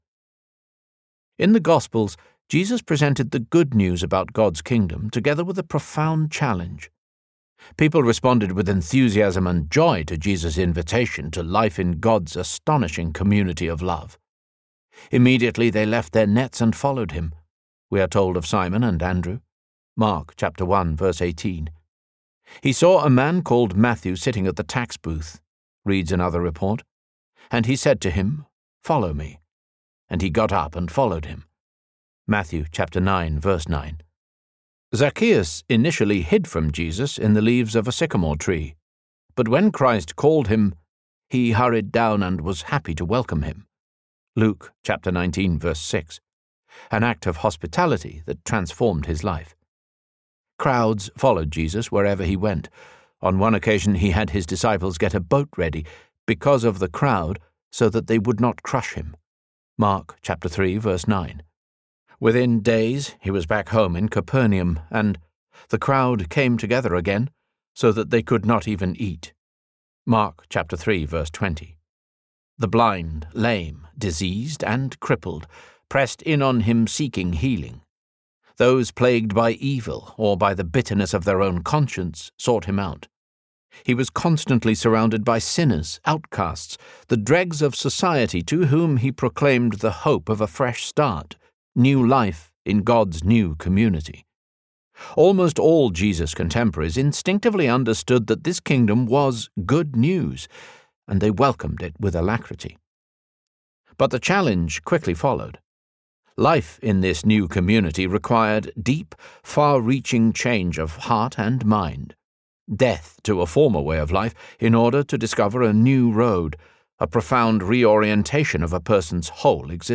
God-Soaked Life Audiobook
Narrator